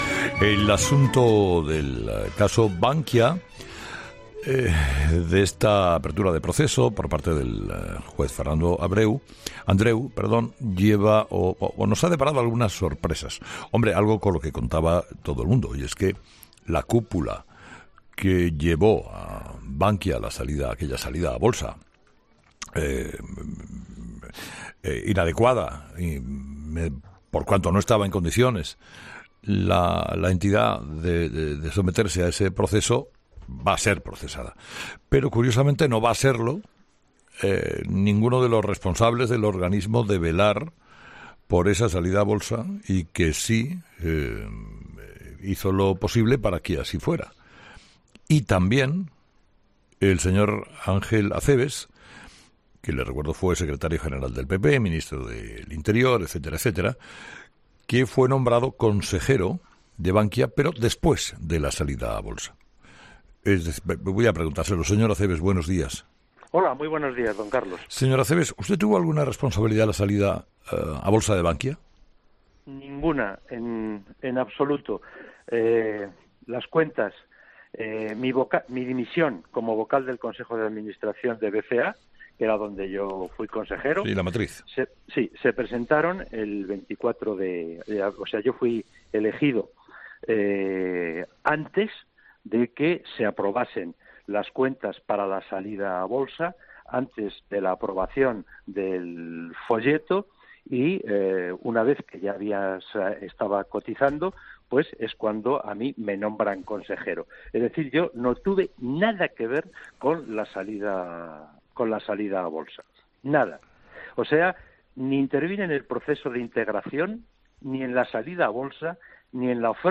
Escucha la entrevista al ex ministro del Interior, Ángel Acebes